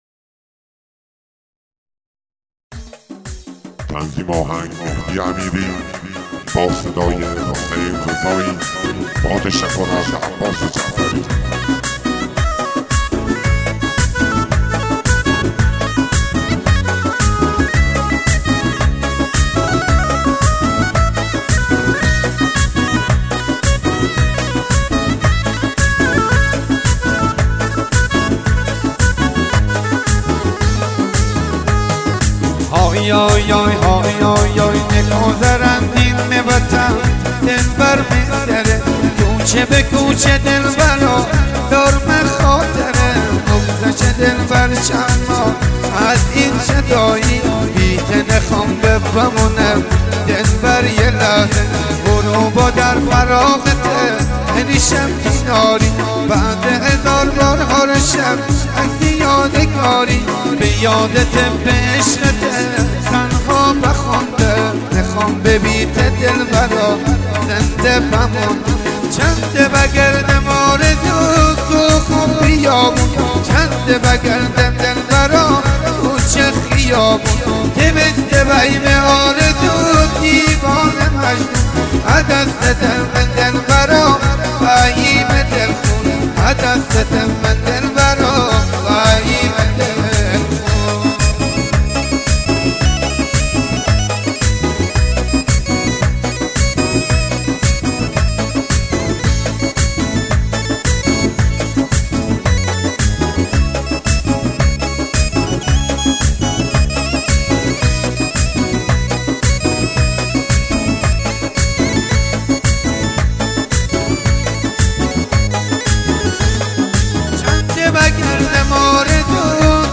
اهنگ مازندرانی